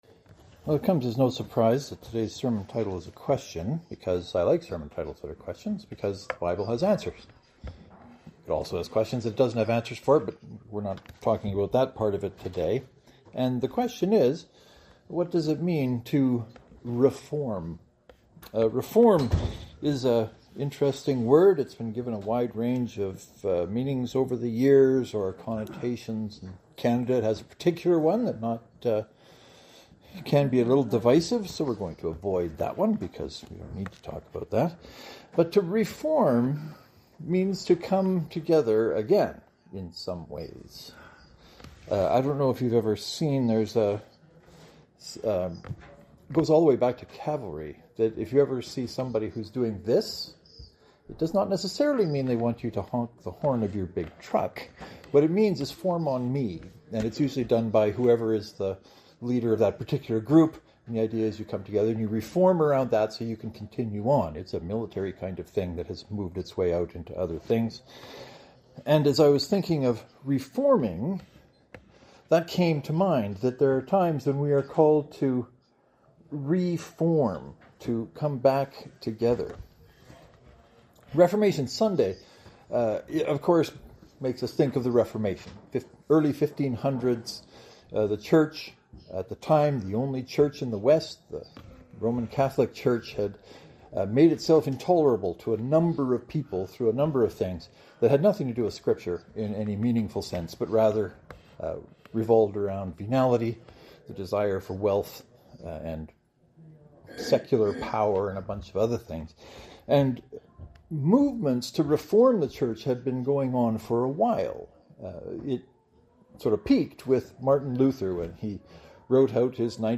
Today’s sermon is only peripherally related to all of that above, as I was more interested in what “reform” means to us as individual people today. Reformation is not just for organizations.